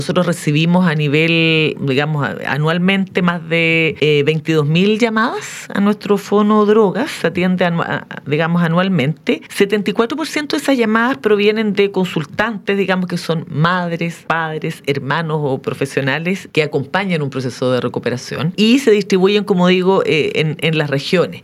En conversación con Radios Regionales, la directora nacional del SENDA, Natalia Riffo, señaló cuál es el mensaje principal que busca transmitir esta segunda temporada de la audioserie.
extracto_entrevista_2.mp3